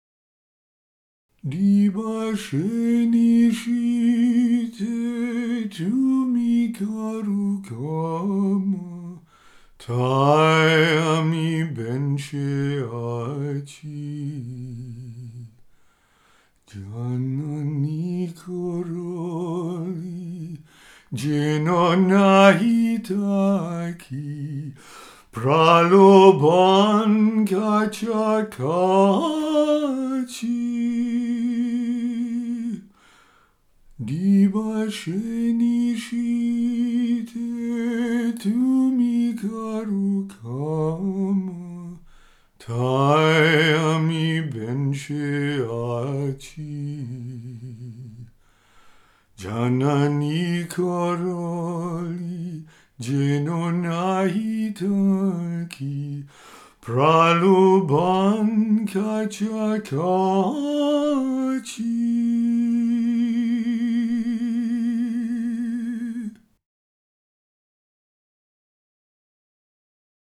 Music for meditation and relaxation.